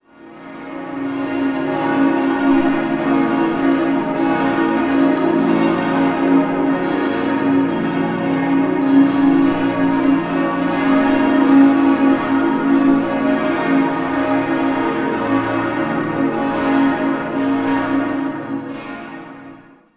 Sehenswert sind die bunten Glasfenster in der Westwand. 5-stimmiges ausgefülltes und erweitertes B-Moll-Geläute: b°-des'-es'-f'-as' Alle Glocken wurden im Jahr 1960 von der Firma Rüetschi in Aarau gegossen.